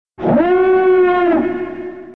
red_alert.mp3